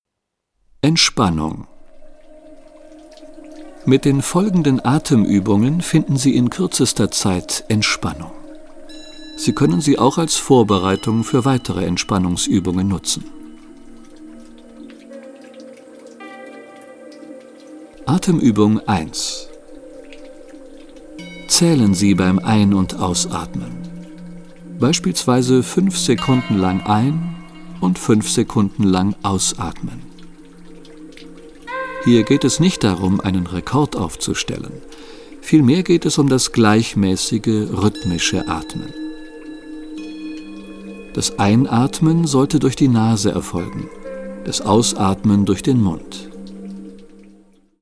Die ruhige und einfühlsame Stimme des Sprechers
führt sie durch die einzelnen Übungen, die mit spezieller Entspannungsmusik unterlegt sind.